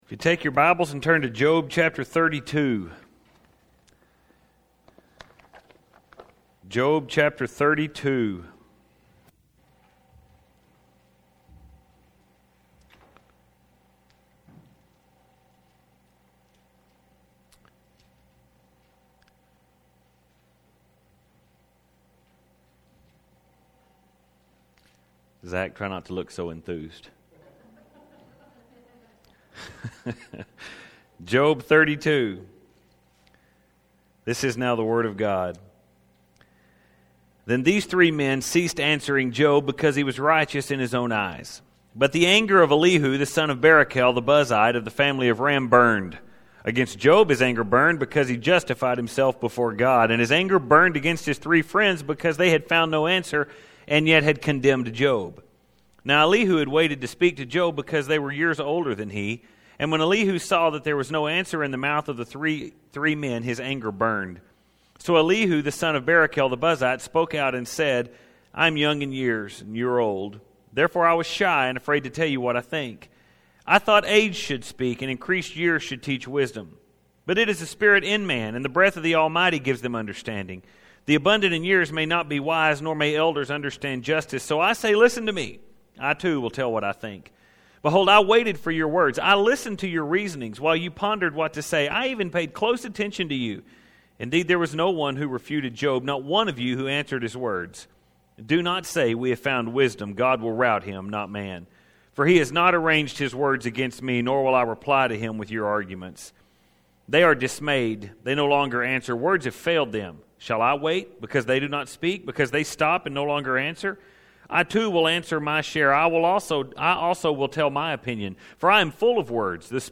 The Sermon of Elihu – part 1 Job 32-37 (32:1-10) February 21, 2016 Tonight we enter a difficult section of the book of Job.